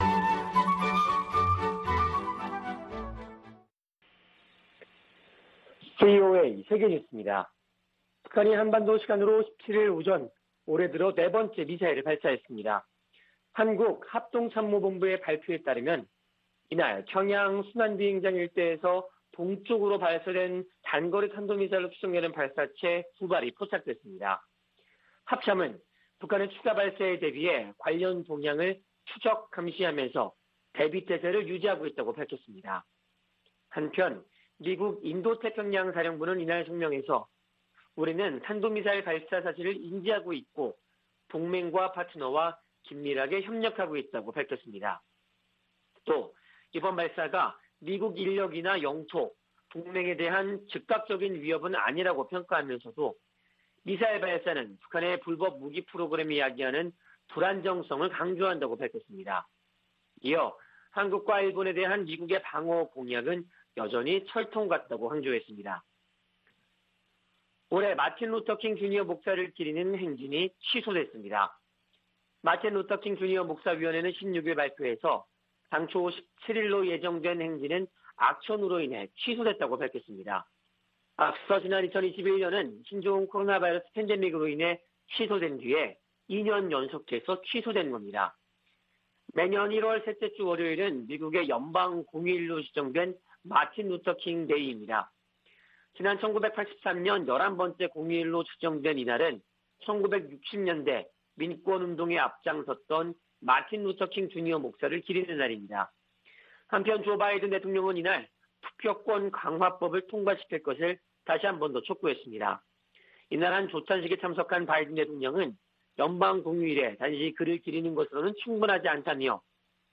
VOA 한국어 아침 뉴스 프로그램 '워싱턴 뉴스 광장' 2021년 1월 18일 방송입니다. 북한이 17 일 또 다시 단거리 탄도미사일 2발을 발사했습니다.